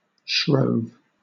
Ääntäminen
Ääntäminen Southern England: IPA : /ʃɹəʊv/ Haettu sana löytyi näillä lähdekielillä: englanti Käännöksiä ei löytynyt valitulle kohdekielelle.